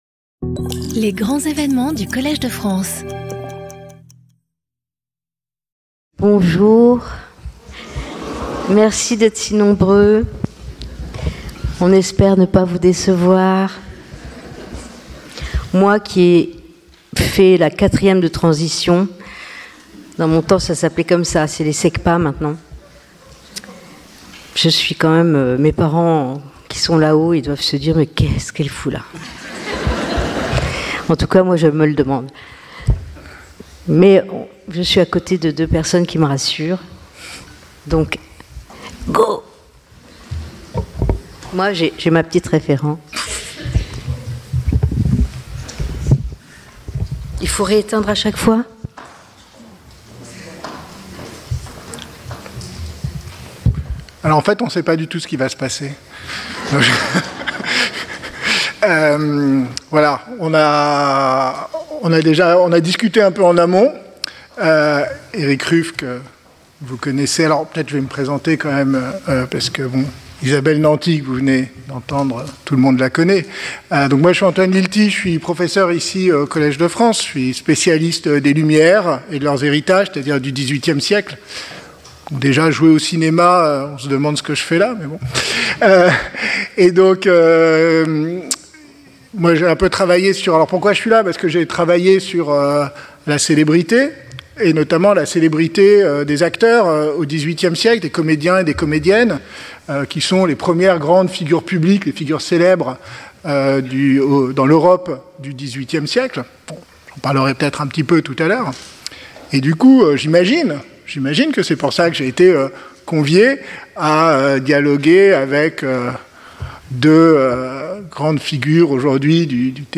Grand événement